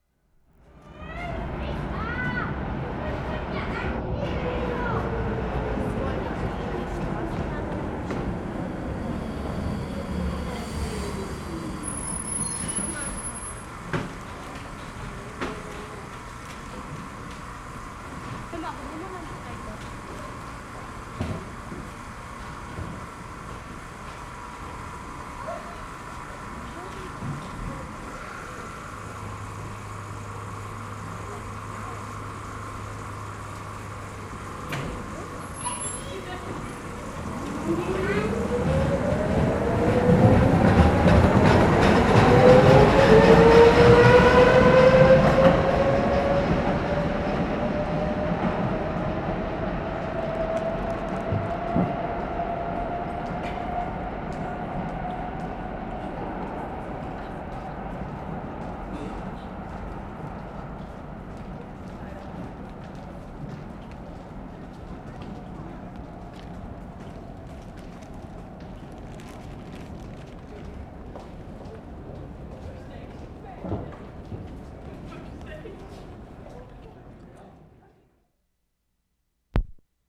Stuttgart, Germany Feb. 27/75
SUBWAY, Charlottenplatz, new car
12. Quite loud for a new car.